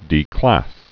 (dē-klăs)